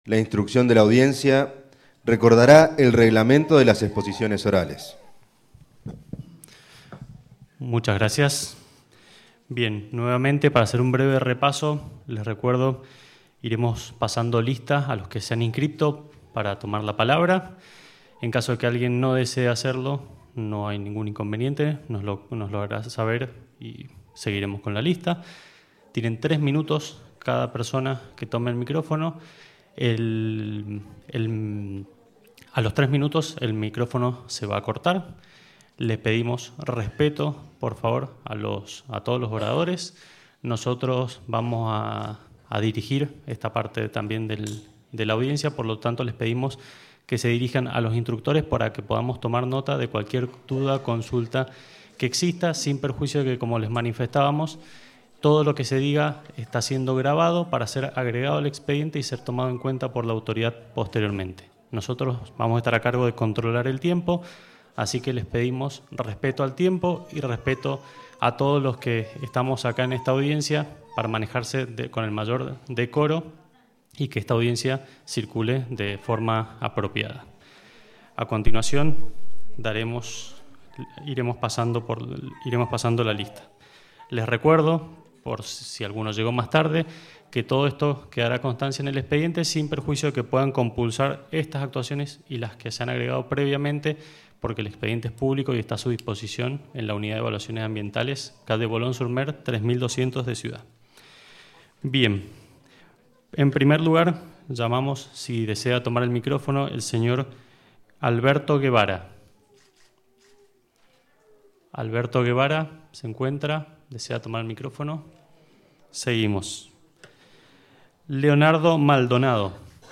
Audio-audiencia-publica.mp3